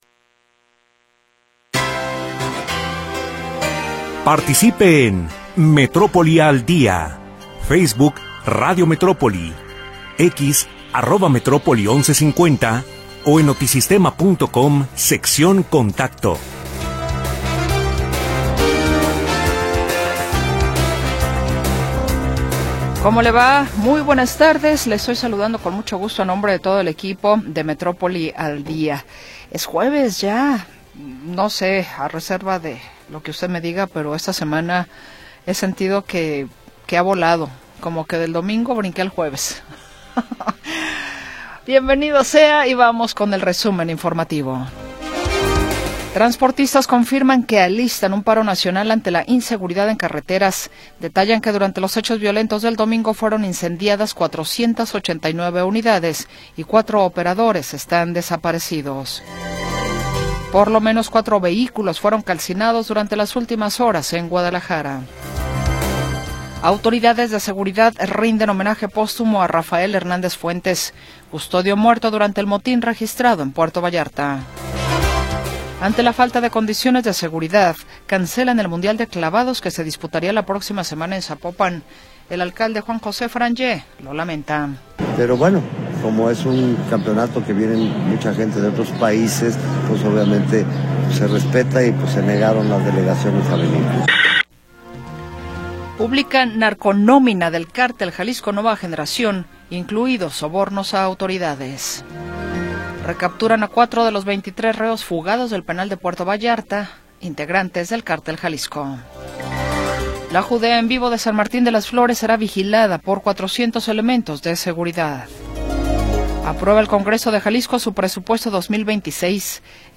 Segunda hora del programa transmitido el 26 de Febrero de 2026.